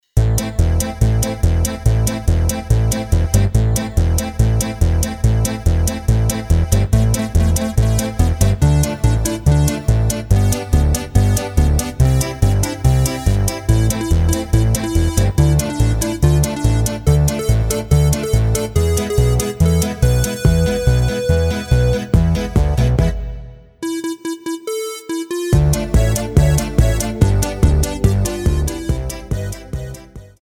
Rubrika: Národní, lidové, dechovka
Karaoke